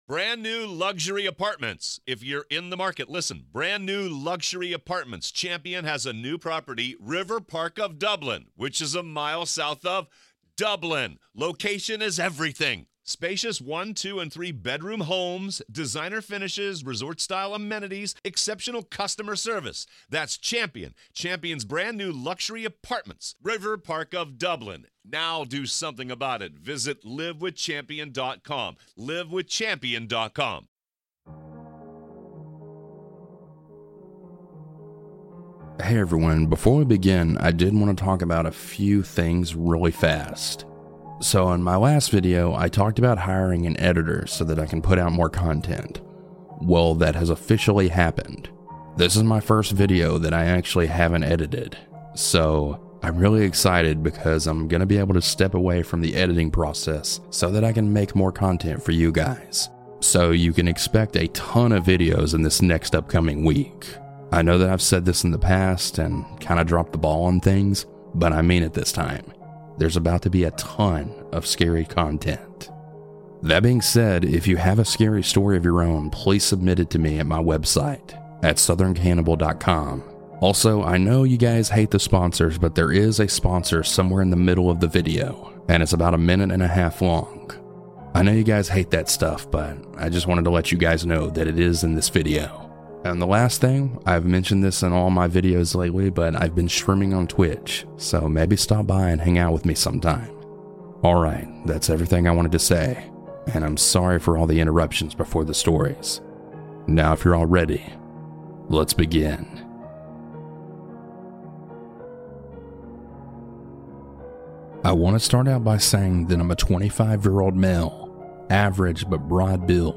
I know I talk a lot at the beginning but I had a lot to say! lol.
- S Huge Thanks to these talented folks for their creepy music!